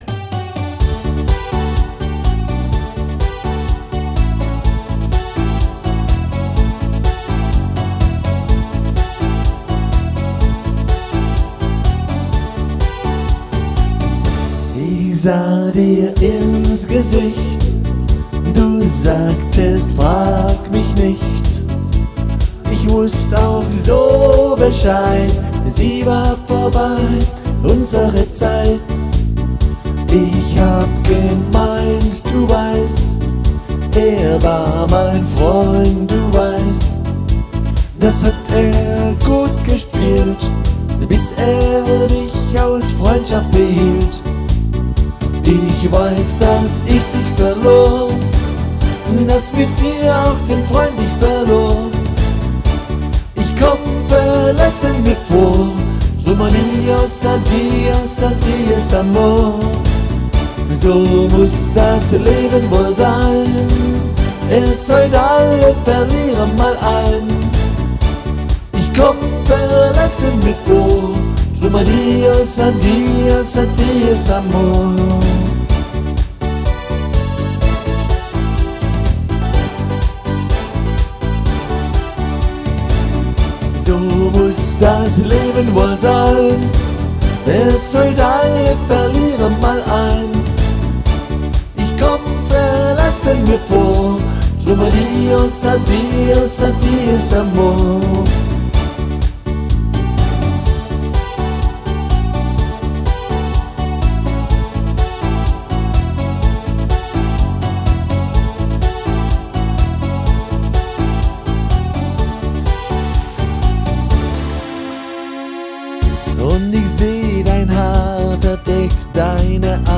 • Alleinunterhalter